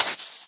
sounds / mob / creeper / say1.ogg